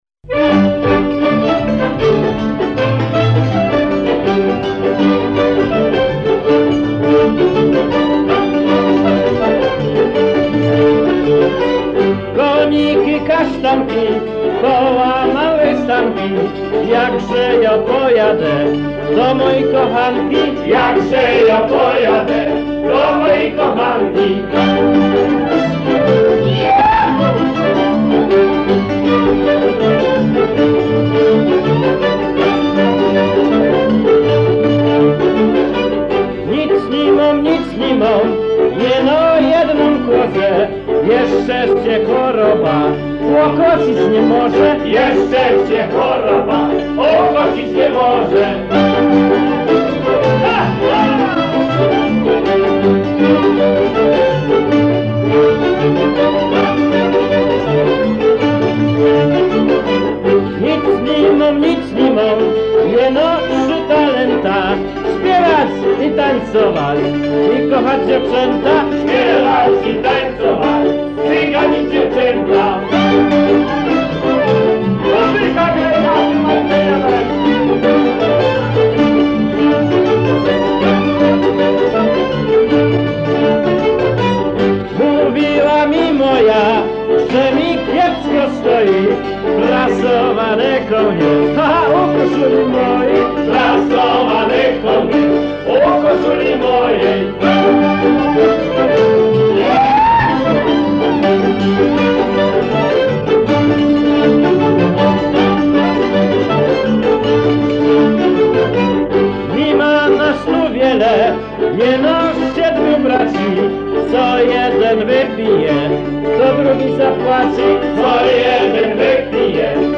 Ponizej pliki dźwękowe mp3 z e zbiorów Muzeum Etnograficznego w Rzeszowie.
2 Kapela Stachy 1977_polka.mp3